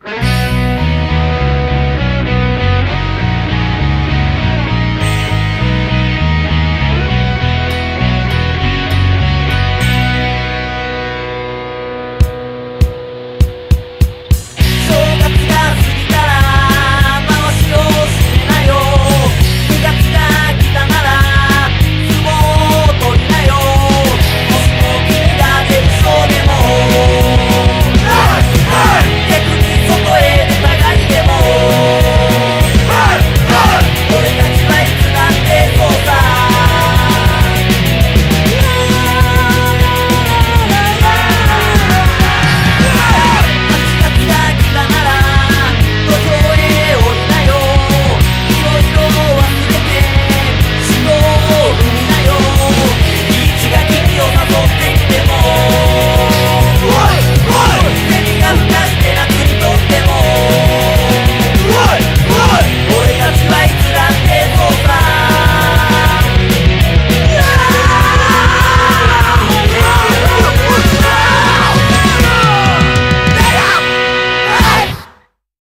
BPM200
Audio QualityLine Out